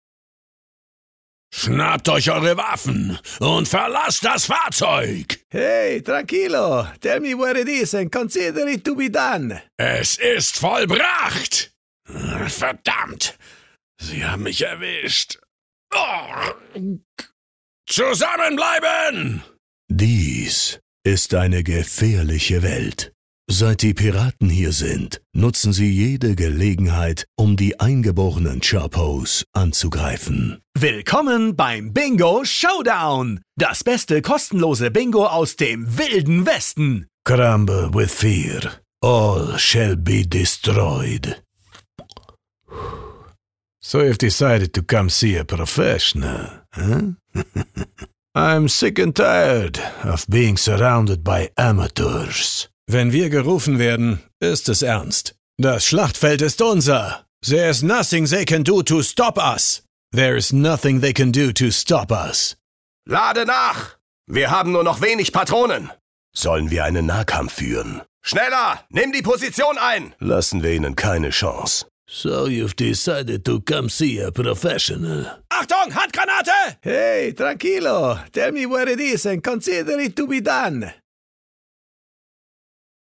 Male
Assured, Character, Confident, Cool, Corporate, Deep, Engaging, Friendly, Gravitas, Natural, Reassuring, Sarcastic, Soft, Wacky, Warm, Witty, Versatile, Young
German (native), Bavarian (native), English with German accent (native), German with English accent, English with South American Accent, German with several European foreign accents.
Microphone: Neumann U87, Neumann M149, Brauner Phantom Anniversary Edition etc.